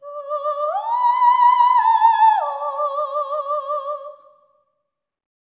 OPERATIC03-R.wav